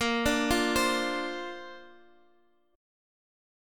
Bbadd9 Chord